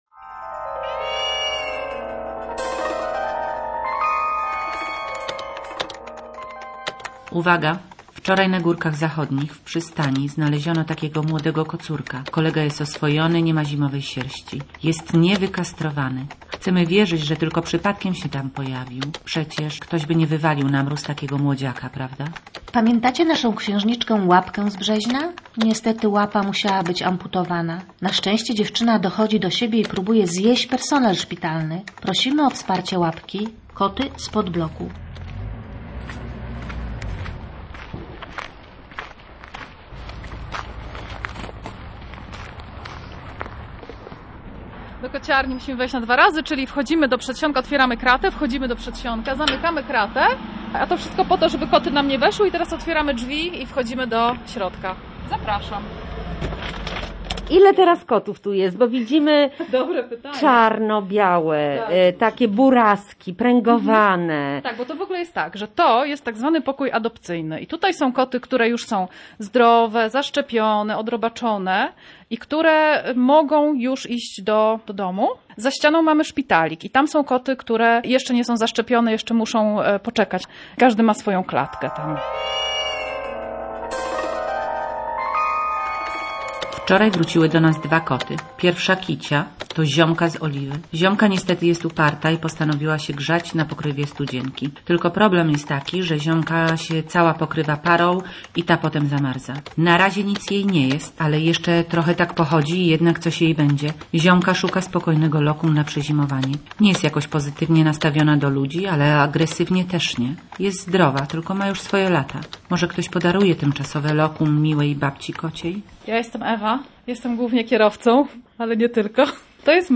Reportaż „Koty spod bloku” to opowieść o czynieniu dobra i empatii wobec zwierzaków, których cierpienia najczęściej powoduje człowiek.